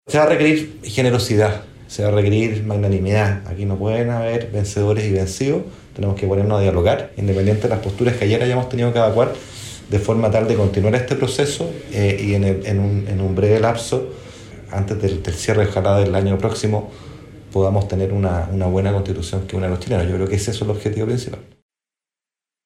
En ese sentido quien aventura una respuesta es el exministro de Hacienda, Ignacio Briones, que estuvo públicamente a favor del Rechazo. En conversación con Radio Bio Bio dio cuenta de qué debería hacer su sector, ahora, para garantizar estabilidad.